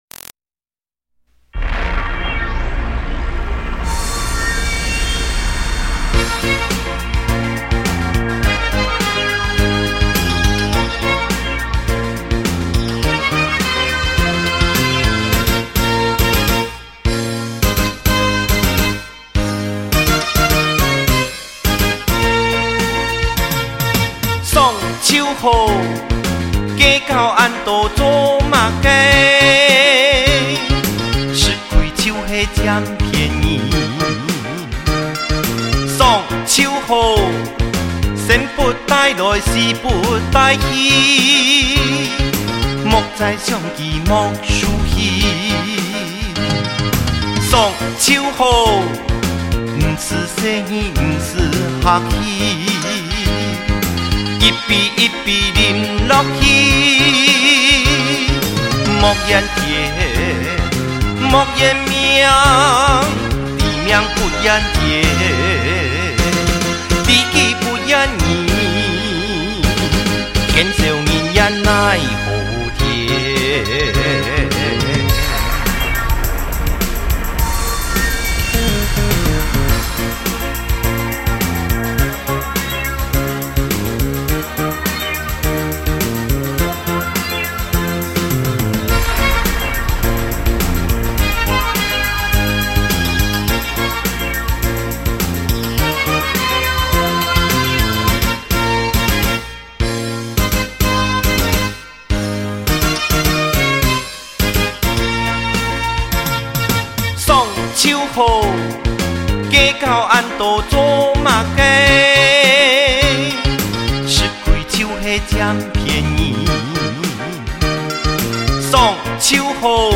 客家歌曲，对我来说，蛮新鲜的感觉~